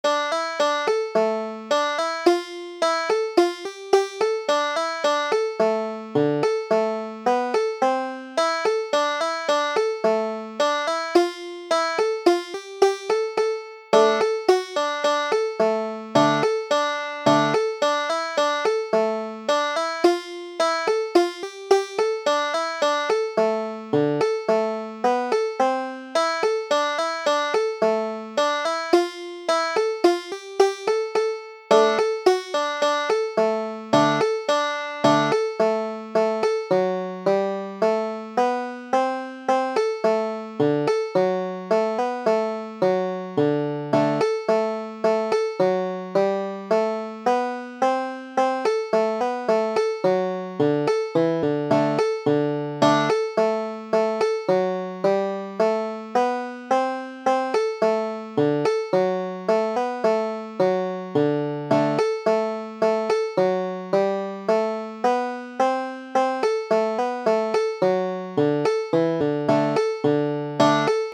Banjo
Hog Eye an' a Tater (Pennsylvania Tune) Double C Dm   tab |